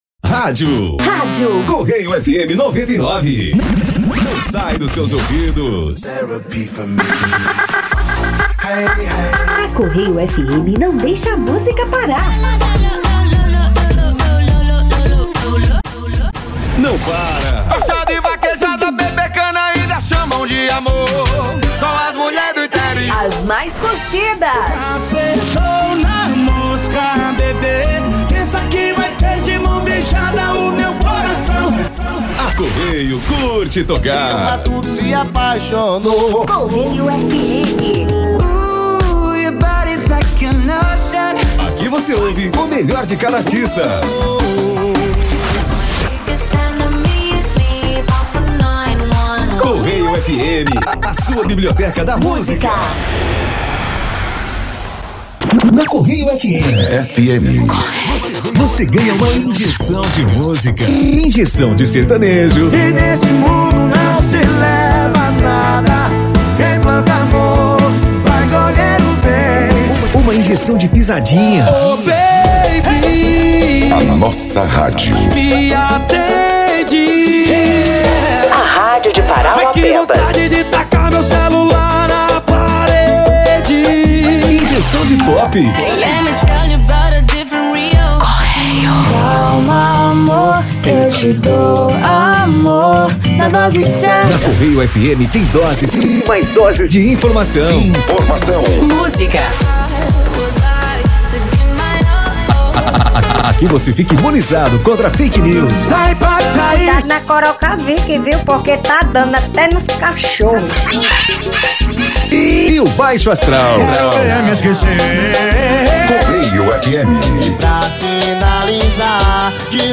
Demo Correio FM - Estilo jovem: